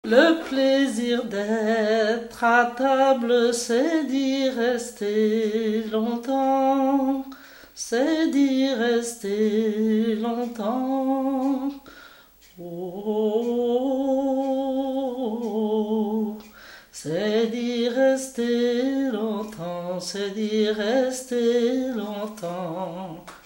Mémoires et Patrimoines vivants - RaddO est une base de données d'archives iconographiques et sonores.
Genre laisse
Pièce musicale inédite